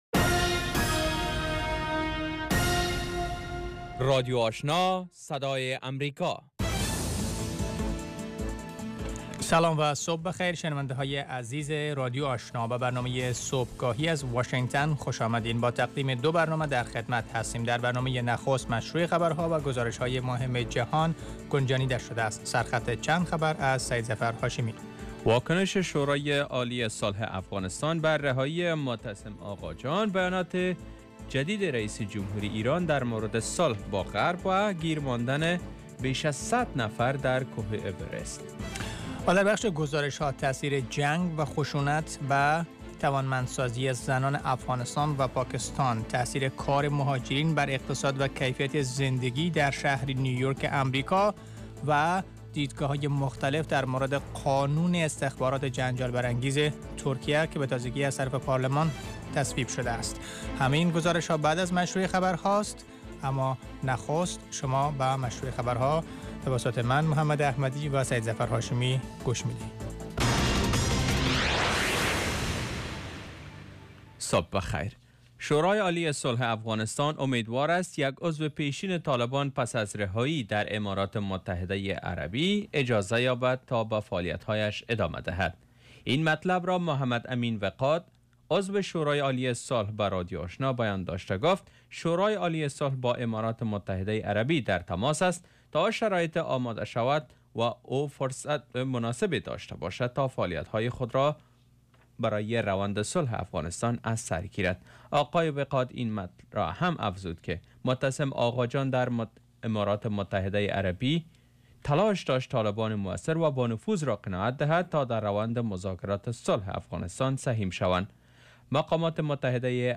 برنامه خبری صبح